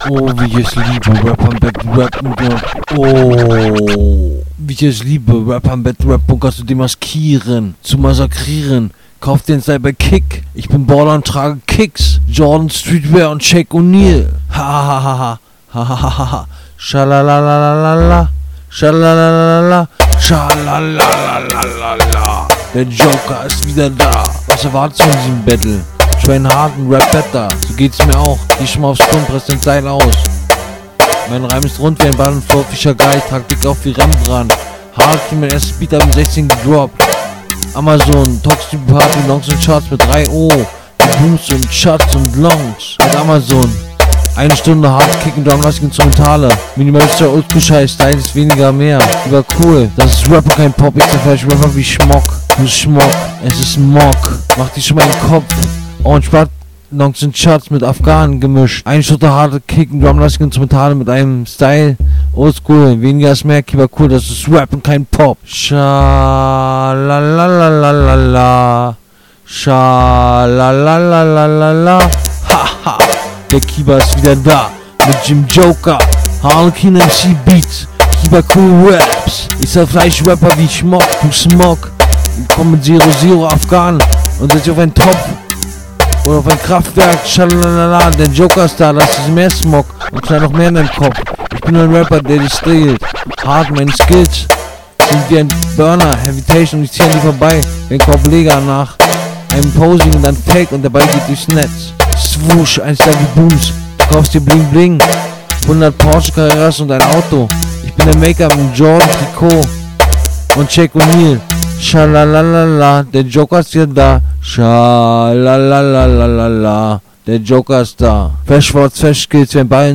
Runde ist nicht auf dem richtigen beat gemacht.
Man kann fast nichts verstehen.